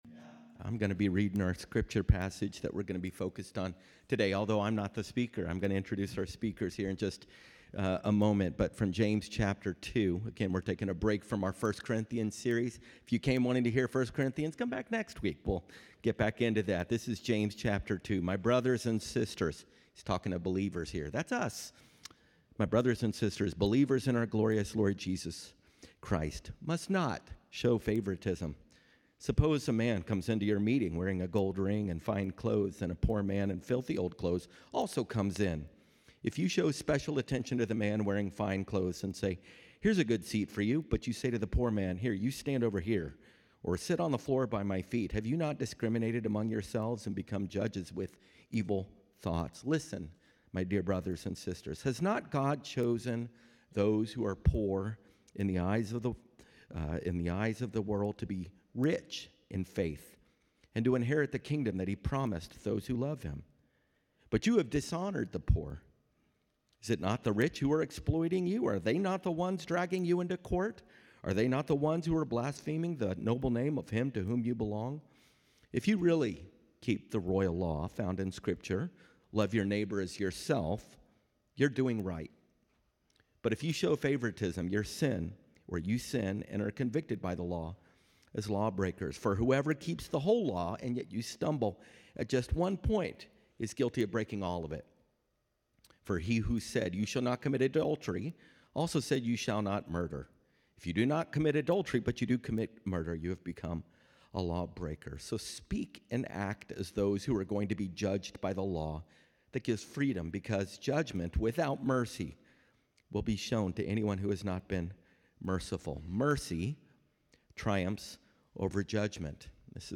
Message: "Called to Compassion"